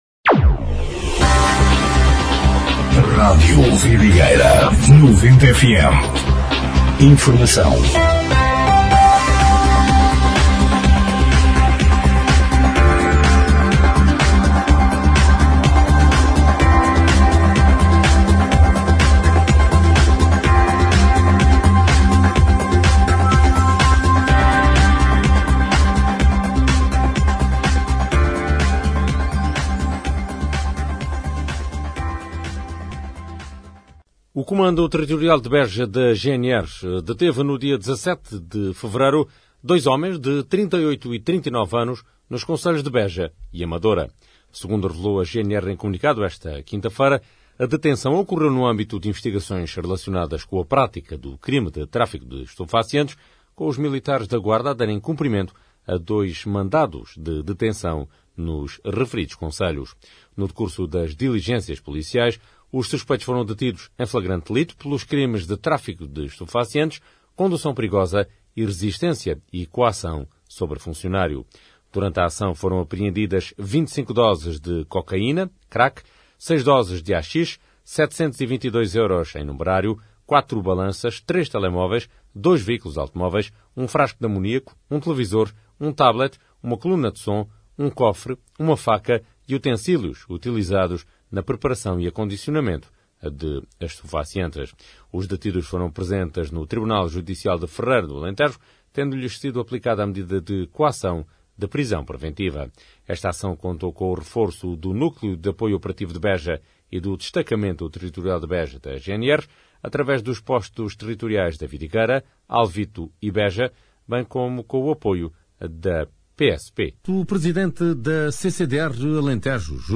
Noticiário 19/02/2026